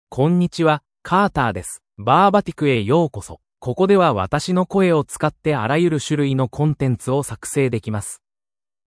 MaleJapanese (Japan)
Carter is a male AI voice for Japanese (Japan).
Voice sample
Carter delivers clear pronunciation with authentic Japan Japanese intonation, making your content sound professionally produced.